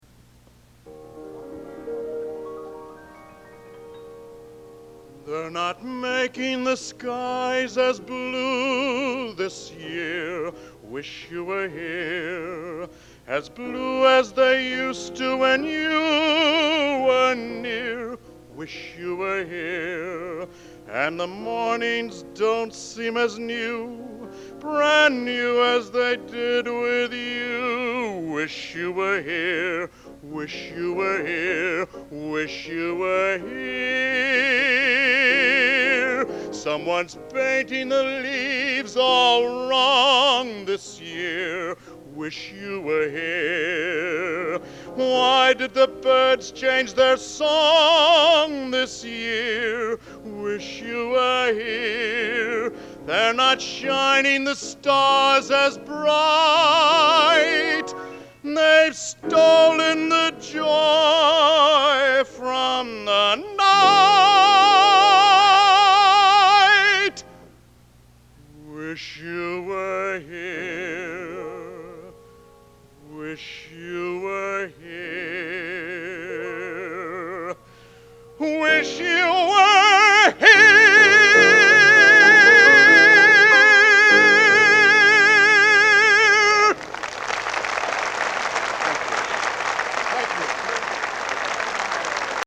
Location: Elliott Hall of Music, West Lafayette, IN